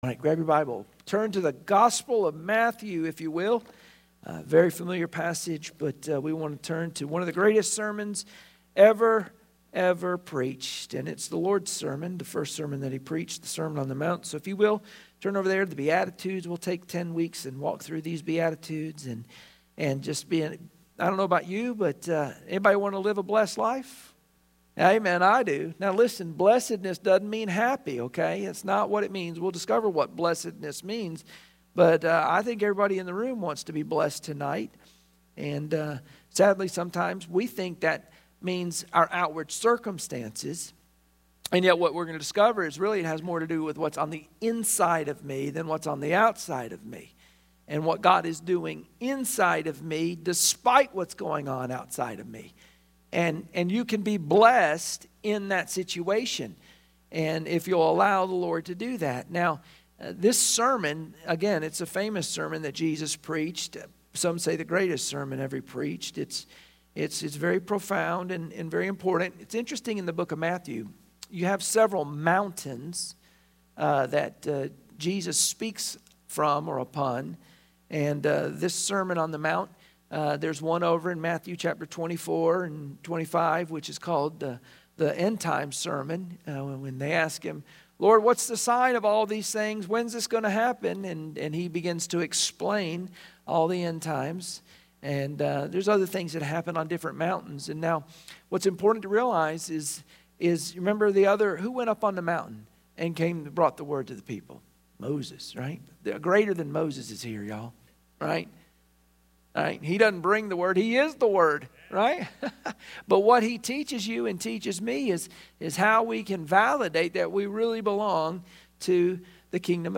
Matthew 5:6 Service Type: Wednesday Prayer Meeting Share this